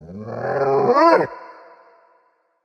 Вы можете скачать или слушать онлайн тревожные и необычные аудиоэффекты в формате mp3.
Звук рычащей нарисованной собаки Cartoon Dog